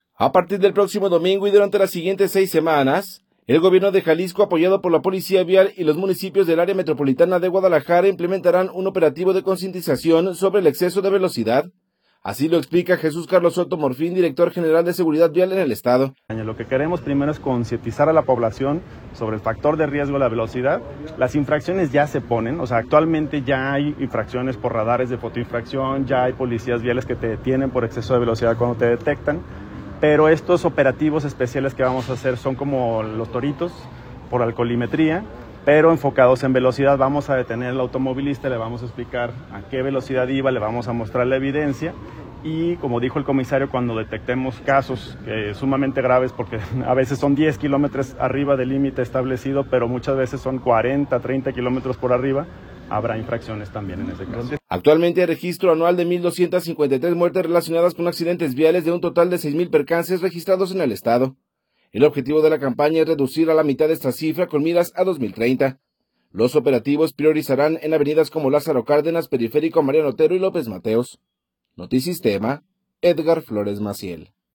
A partir del próximo domingo y durante las siguientes seis semanas, el gobierno de Jalisco apoyado por la Policía Vial y los municipios del Área Metropolitana de Guadalajara, implementarán un operativo de concientización sobre el exceso de velocidad. Así lo explica Jesús Carlos Soto Morfín, director general de Seguridad Vial en el estado.